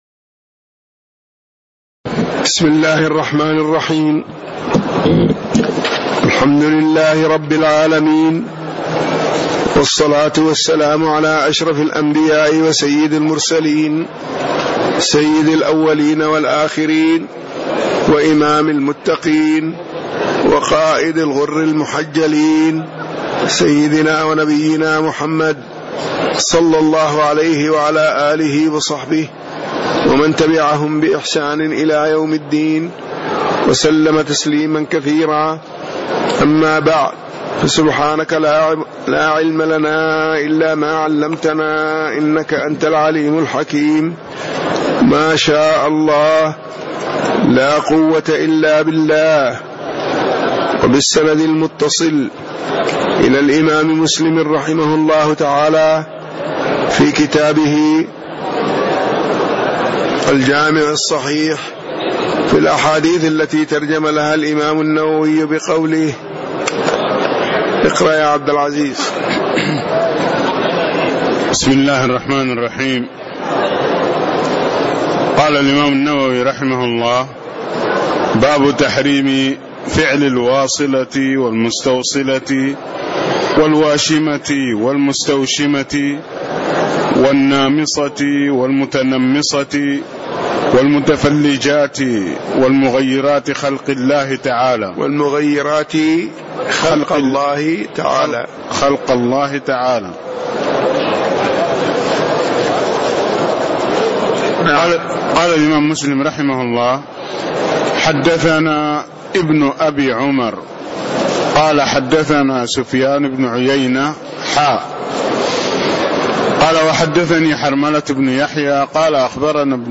تاريخ النشر ٤ ذو القعدة ١٤٣٦ هـ المكان: المسجد النبوي الشيخ